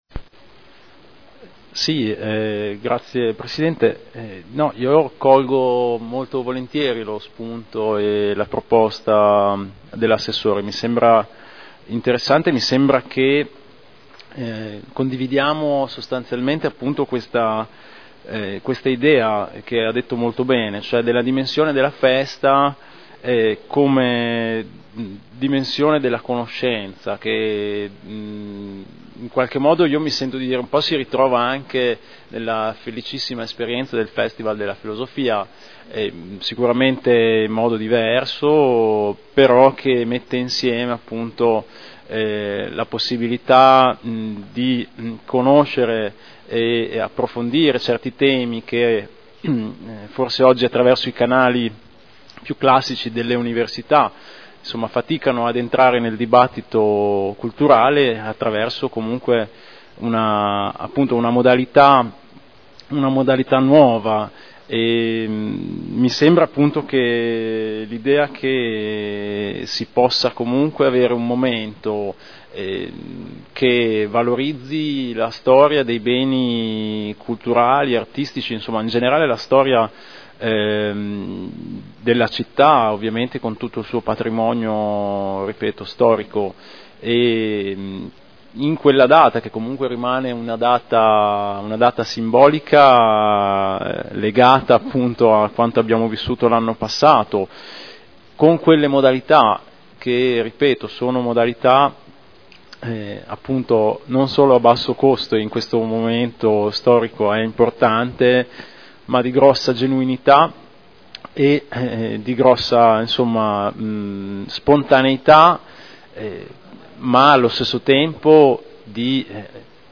Stefano Rimini — Sito Audio Consiglio Comunale
Seduta del 12/04/2012. Replica a risposta Assessore Alperoli.